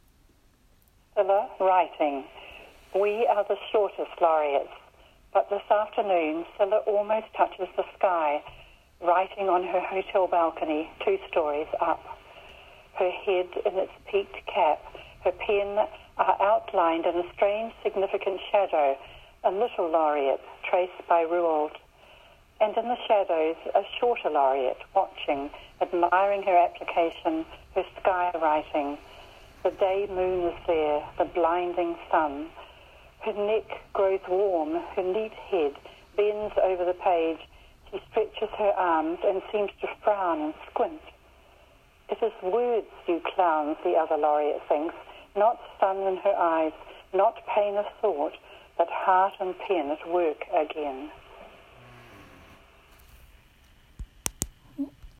Elizabeth reads ‘Cilla, writing’